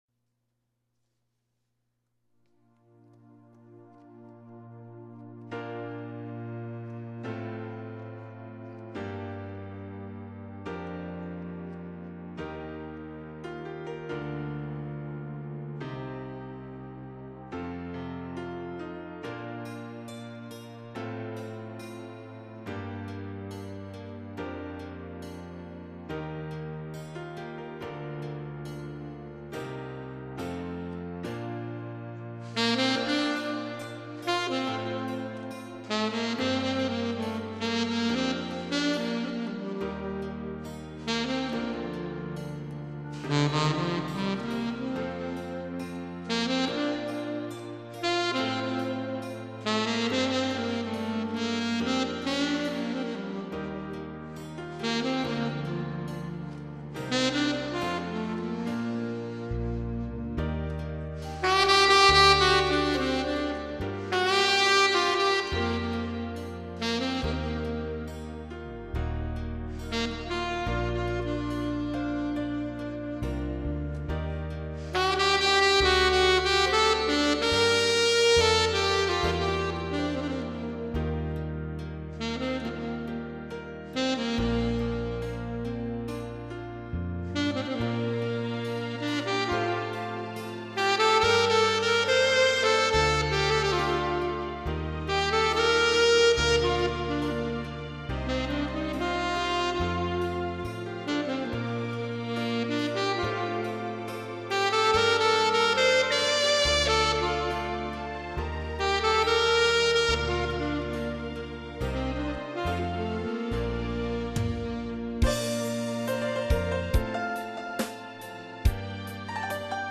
롱톤 스켈연습없이 막바로 반주기에서 이노래 켜놓고 5-6번 연습....